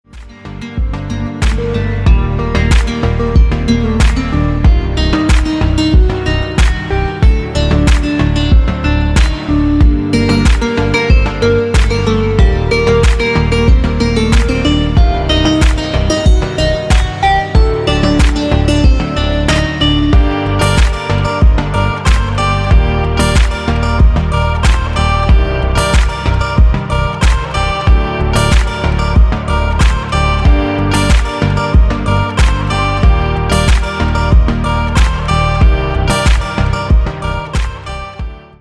• Качество: 320, Stereo
гитара
мелодичные
без слов
инструментальные
Стиль: oriental house